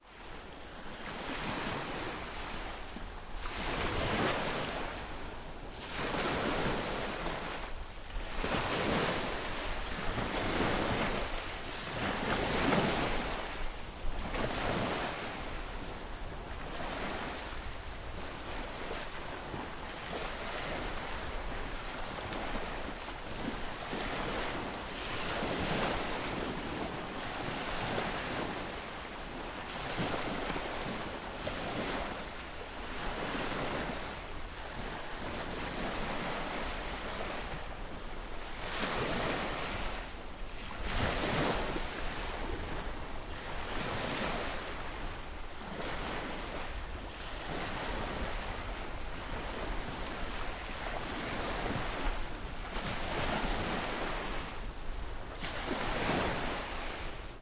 塞萨洛尼基 " 氛围扩展，离海很近
描述：海浪的现场记录。 （放大H4n）
Tag: 沙滩 海浪 环境 波浪 海洋 海岸 海岸 海洋 海边 现场录音